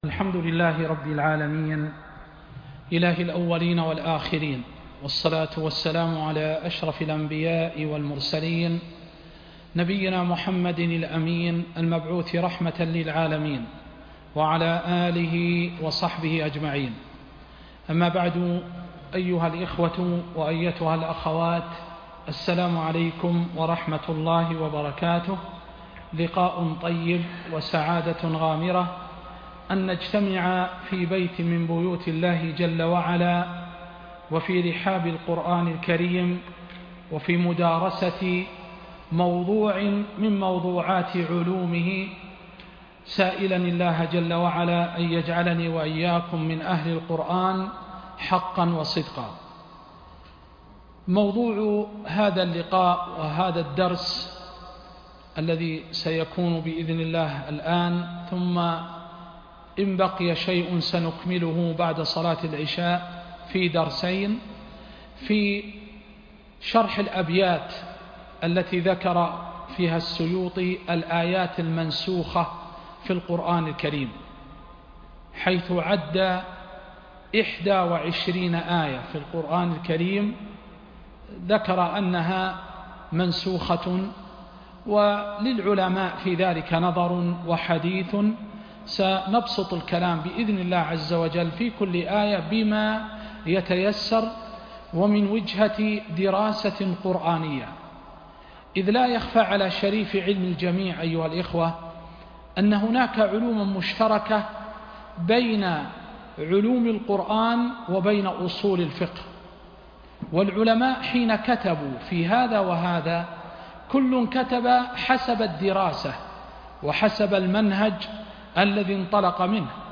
درس في شرح الآيات التي قيل إنها منسوخة1